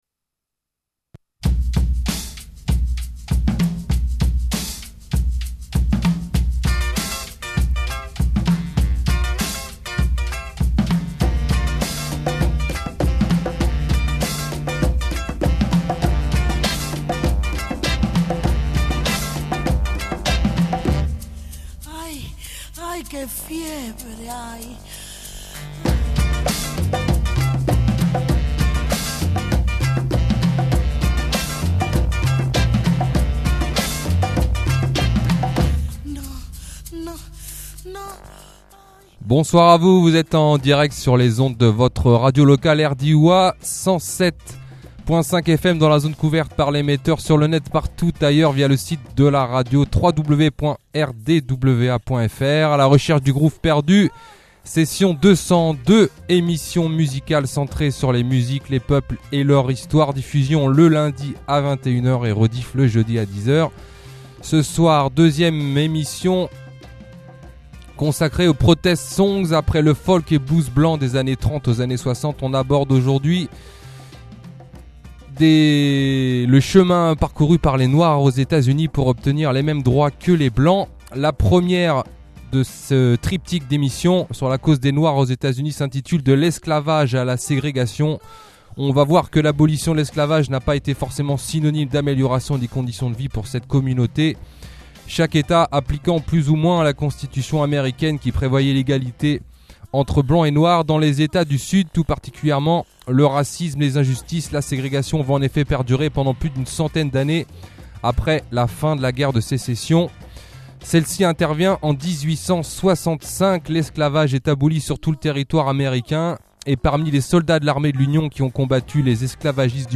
L’histoire du jour est contée sur fond de blues, de jazz, de reggae et de funk.